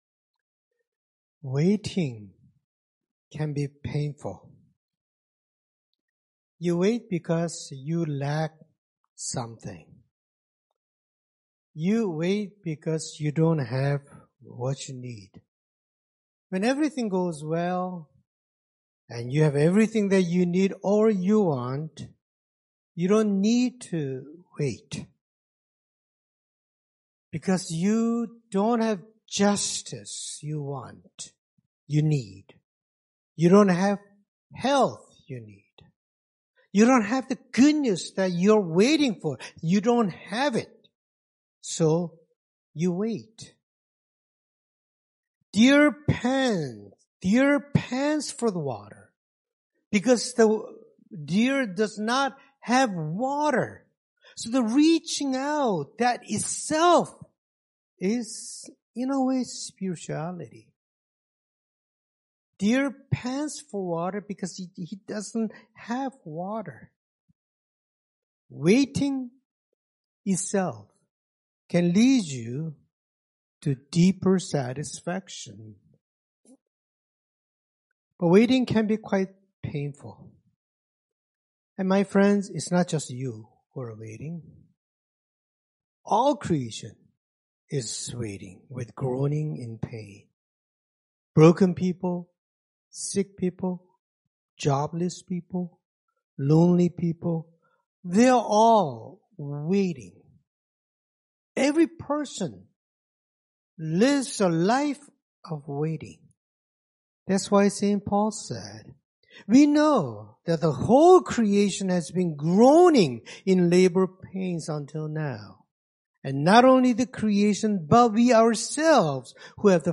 Scripture Passage 1 Samuel 1:4-20 Worship Video Worship Audio Sermon Script The sermon script is currently unavailable.